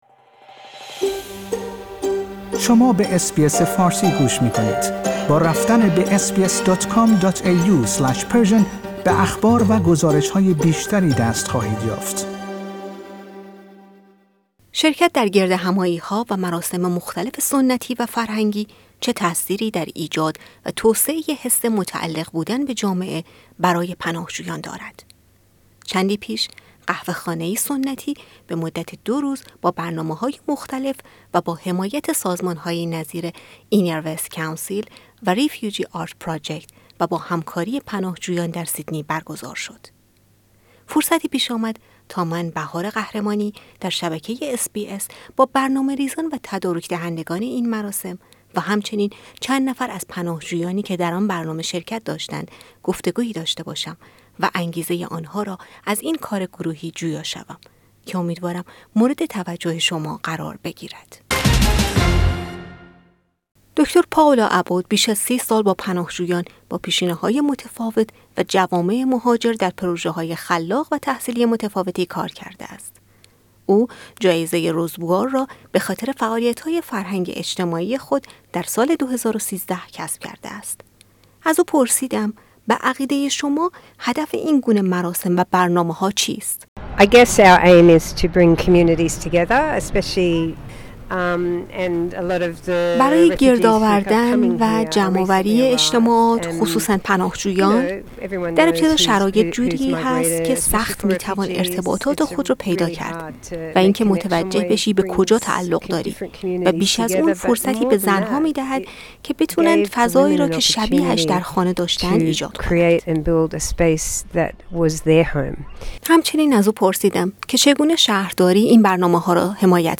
فرصتی پیش آمد تا با چند نفر از فعالین این مراسم گفت و گویی داشته باشیم.